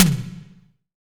Index of /90_sSampleCDs/Best Service - Real Mega Drums VOL-1/Partition G/SDS V TOM ST